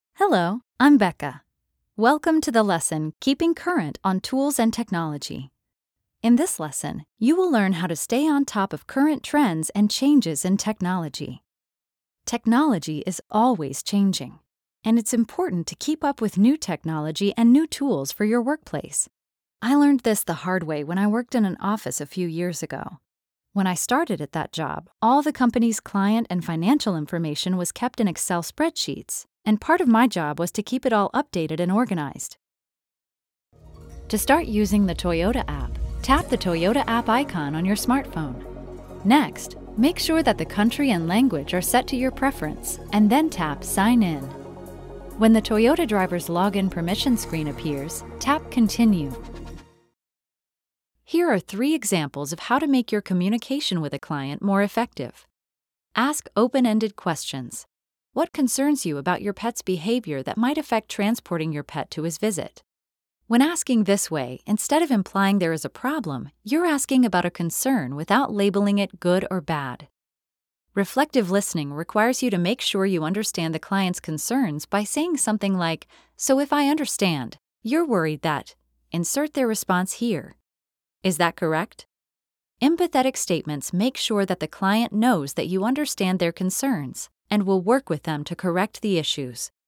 standard us
e-learning